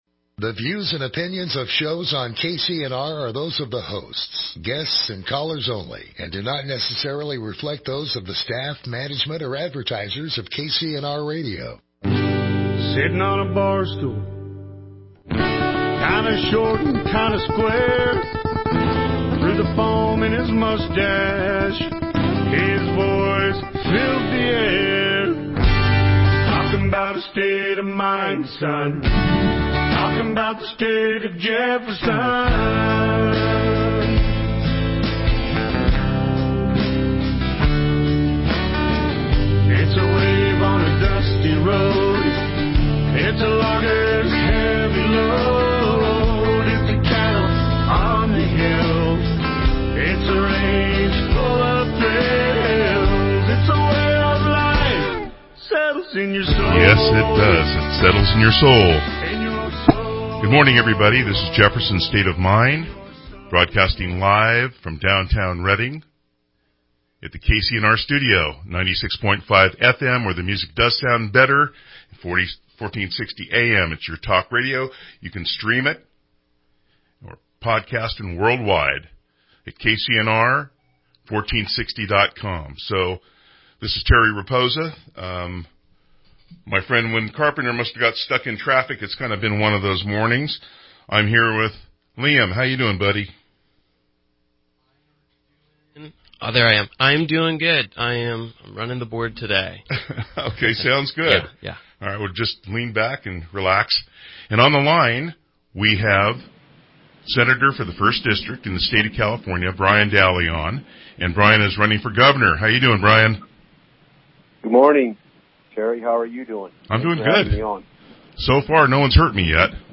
Guests: Phone in Doug Lamalfa